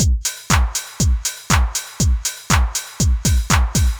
Index of /musicradar/retro-house-samples/Drum Loops
Beat 03 Full (120BPM).wav